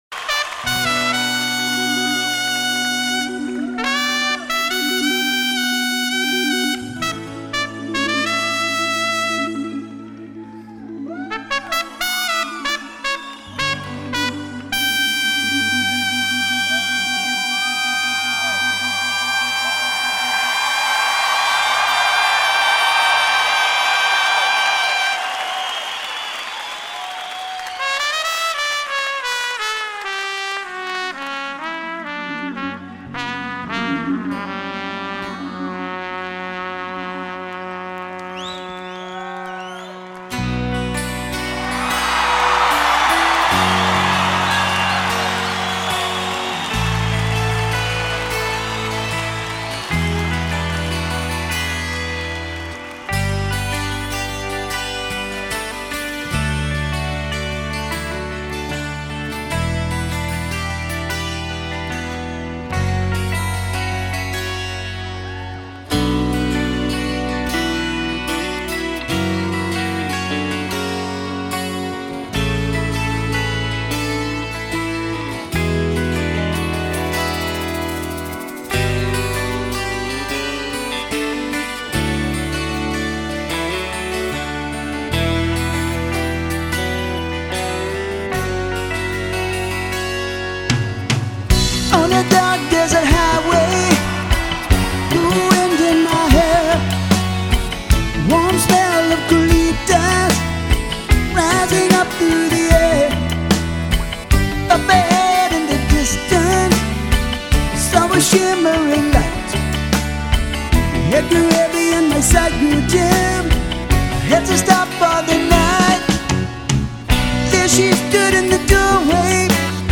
live acoustic